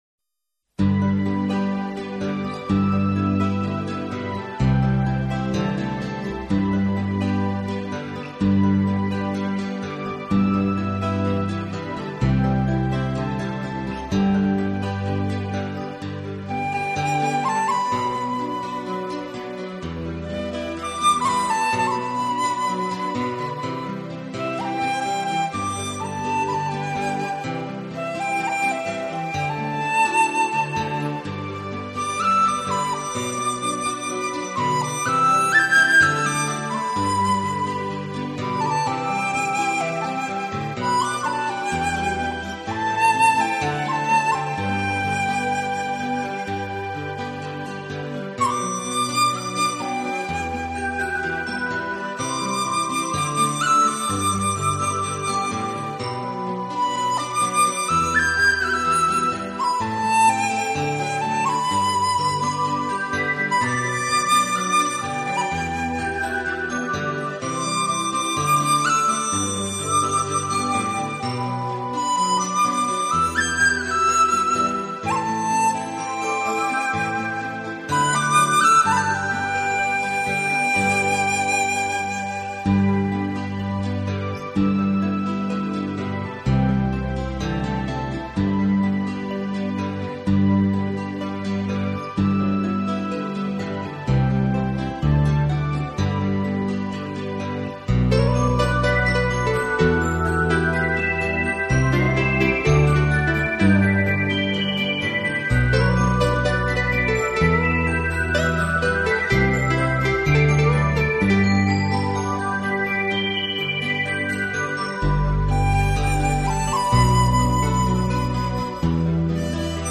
笛子是吹奏乐器。